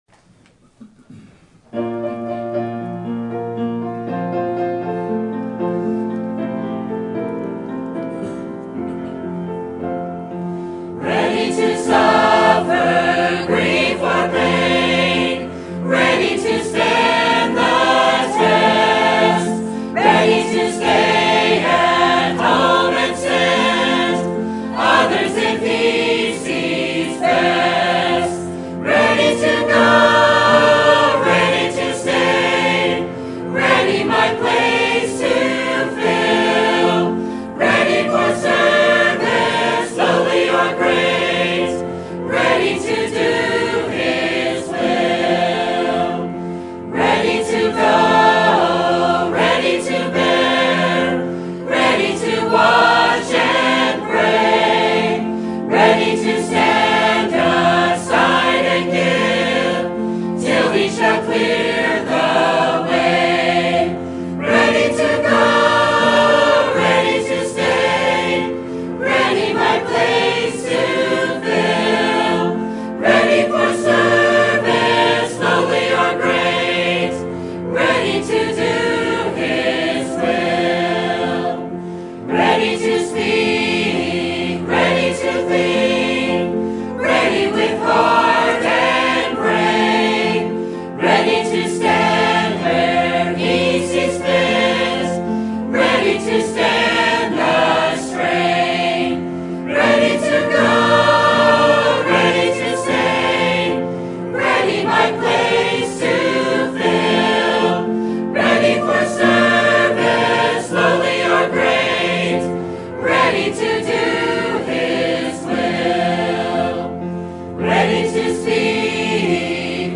Sermon Topic: Missions Conference Sermon Type: Special Sermon Audio: Sermon download: Download (16.64 MB) Sermon Tags: 2 Kings Gospel Missions Witness